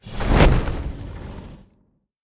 npc_dragon_wingflap_04.wav